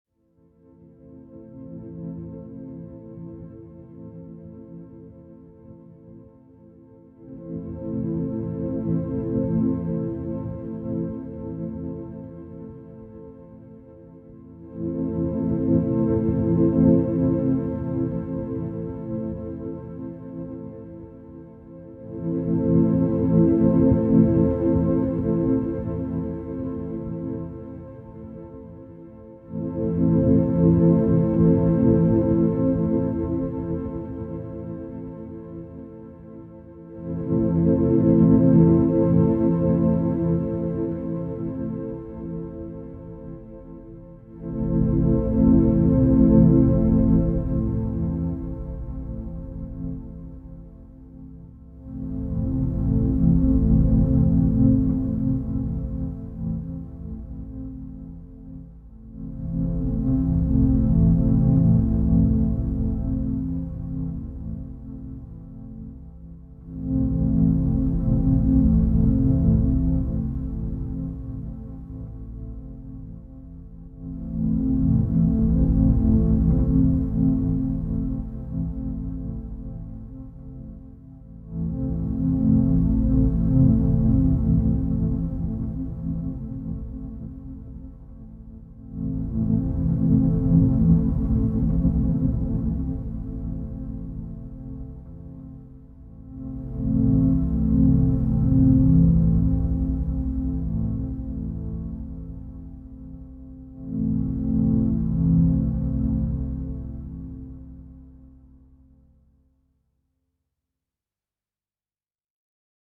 Shimmering Synth Drone Version